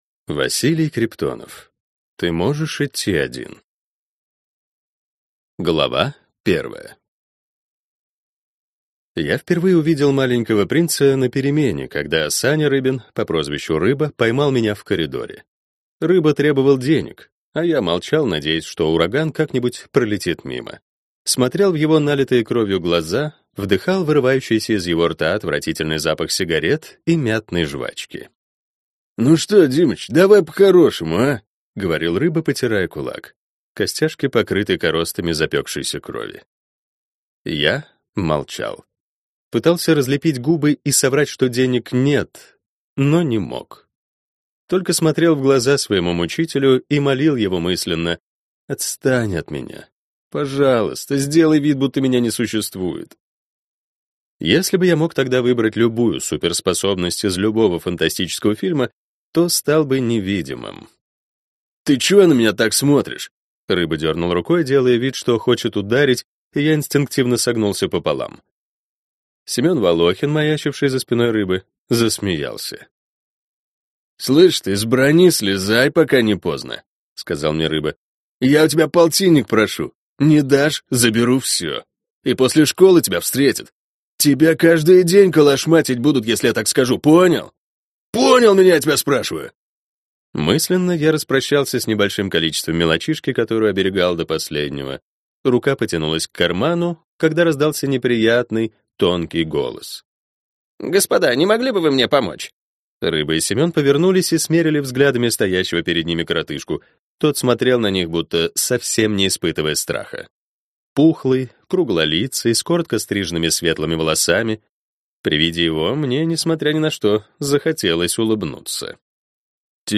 Аудиокнига Ты можешь идти один | Библиотека аудиокниг